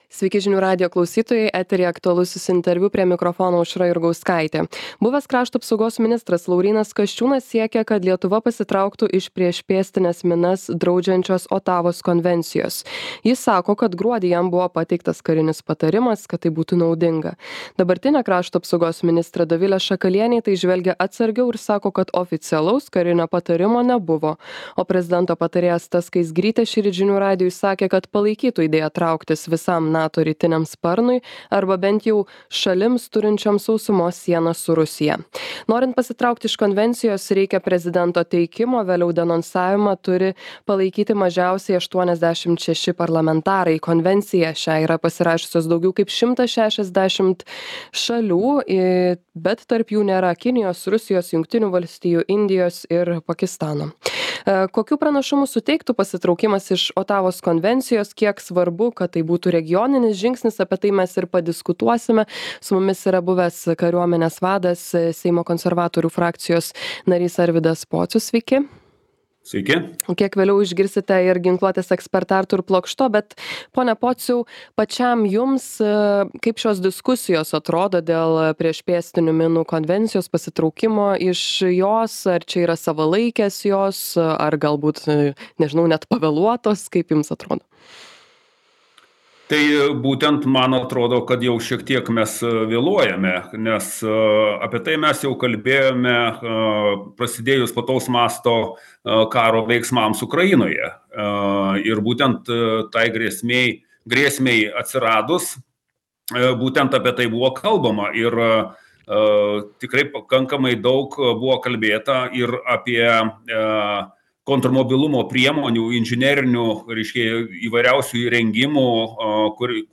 Aktualusis interviu